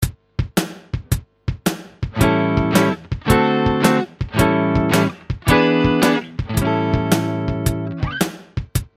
One final blues rhythm element is to vary the chords used in the last 2 bars of the 12 bar structure.
Blues Turnaround 1 | Download
blues_turnaround1.mp3